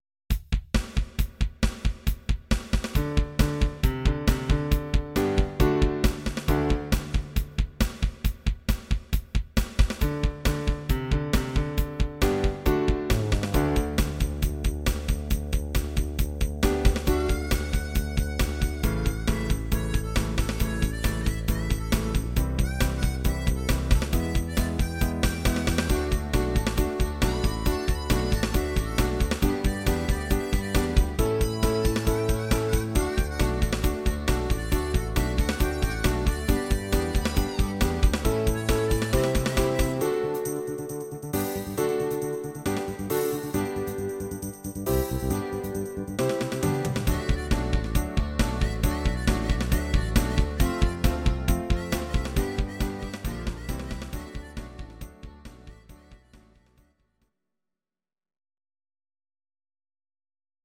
Audio Recordings based on Midi-files
Instrumental, 1970s